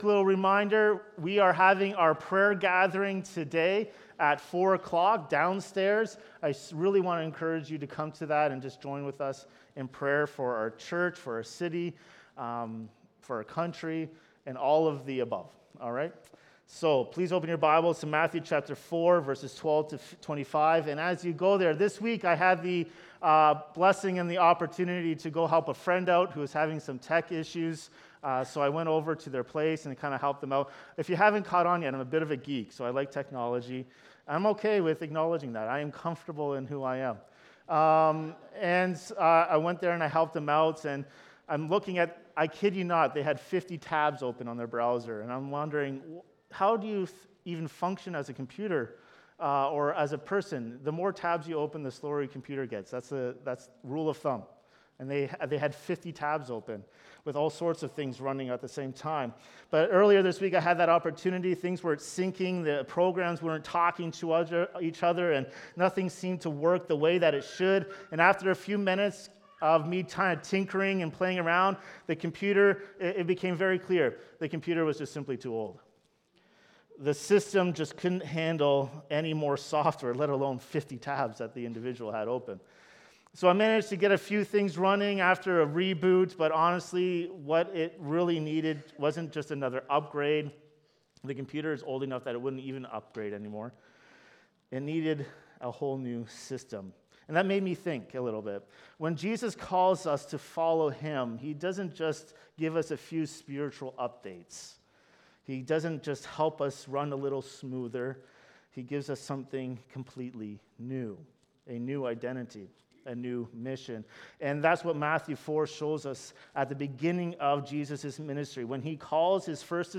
The Call of Jesus: A New Identity and Mission | Matthew 4:12–25 Sermon